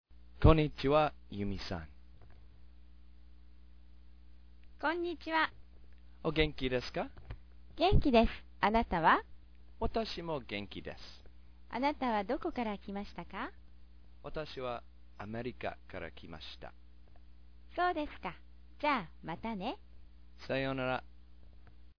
Kaiwa sound file -- 88 kb